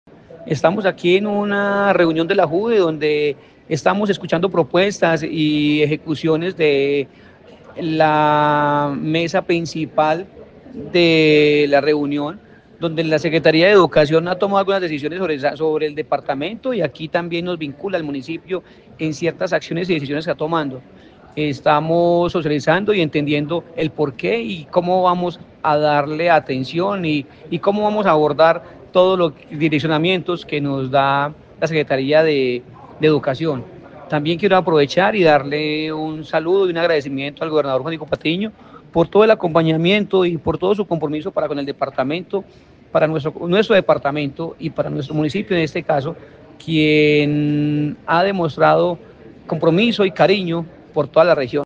En la reciente Junta Departamental de Educación (JUDE) 2024 se presentó un balance de los logros alcanzados en el sistema educativo de Risaralda, con énfasis en la mejora de la calidad educativa, el bienestar docente y la cobertura.
MIGUEL-BEDOYA-ALCALDE-DE-SANTUARIO.mp3